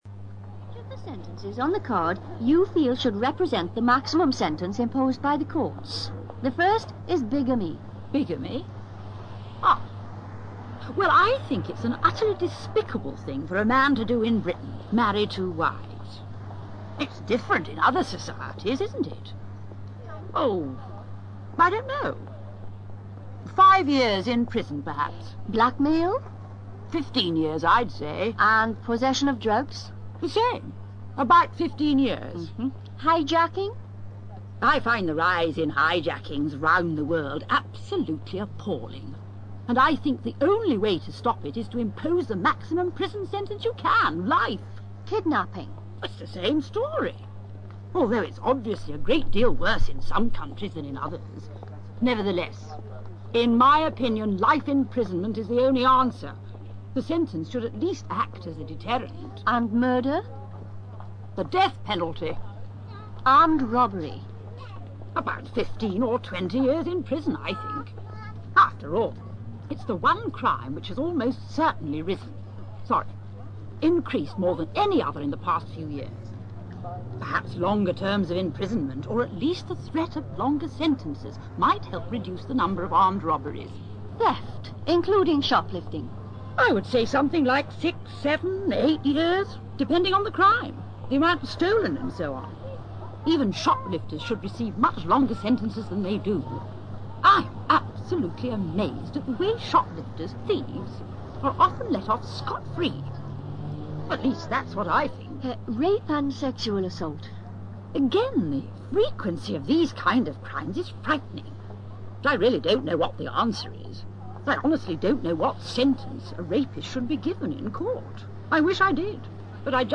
ACTIVITY 75: You are going to hear two people (a man and a woman) being interviewed in the street as part of an opinion poll to discover the attitudes of the general public to certain social problems.
WOMAN